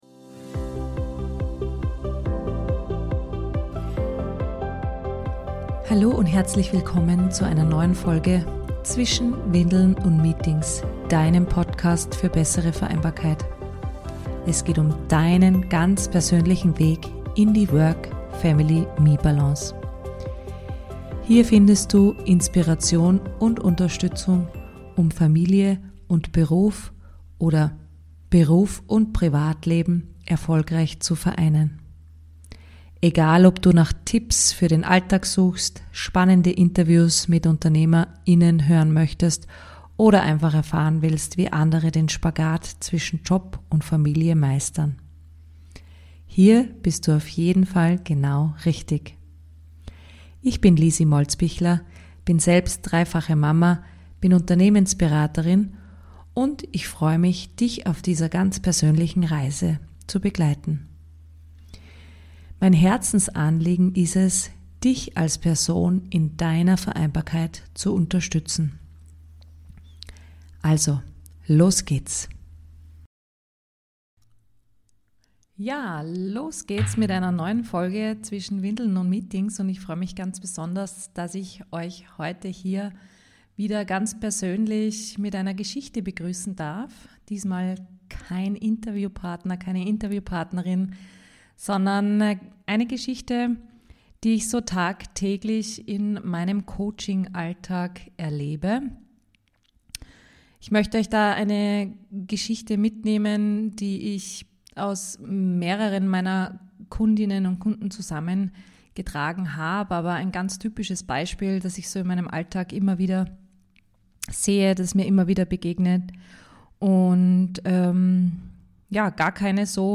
Was tun, wenn Vereinbarkeit zur Zerreißprobe wird – und trotzdem Führung gefragt ist? In dieser Solo-Episode erzähle ich Dir Geschichten aus meinem Coaching-Alltag und wie wir zwischen Job, Kindern und Pflege fast untergehen – und wie wir wieder zurück zur Kraft finden können.